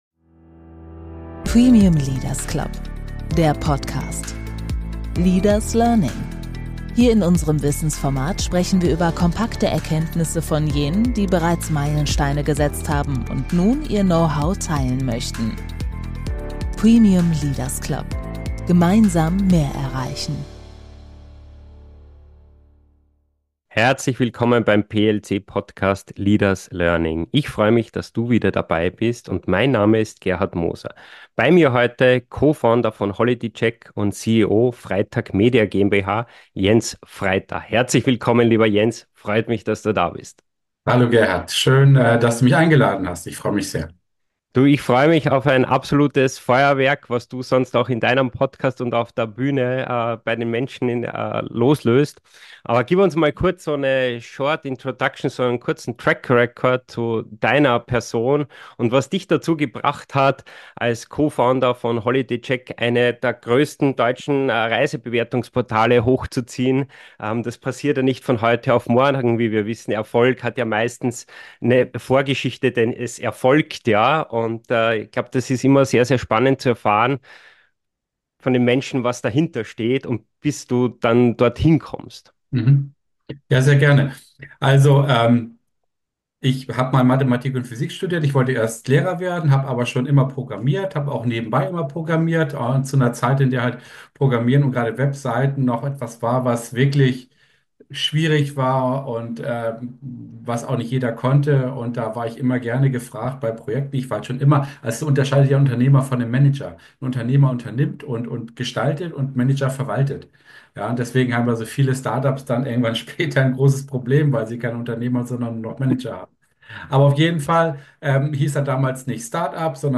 Ein inspirierendes Gespräch für alle, die sich für Unternehmertum, Innovation und die Chancen von Künstlicher Intelligenz interessieren!